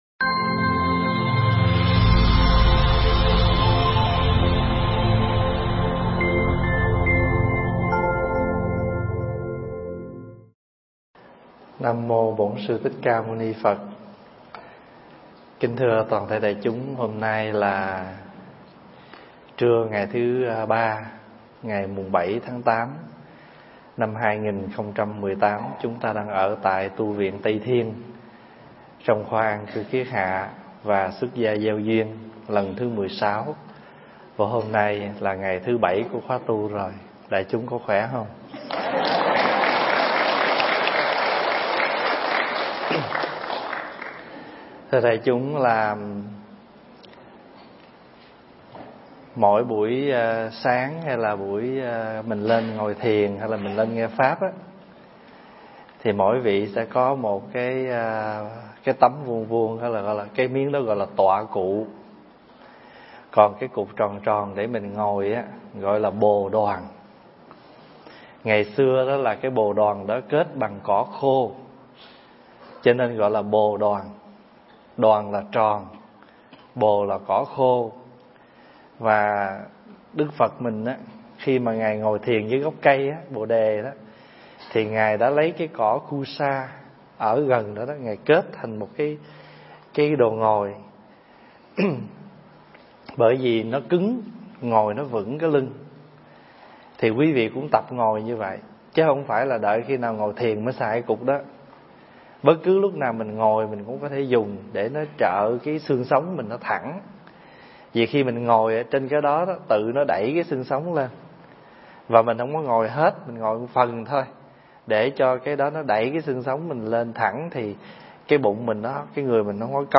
Nghe Mp3 thuyết pháp Buông
thuyết giảng tại Tu Viện Tây Thiên (Canada)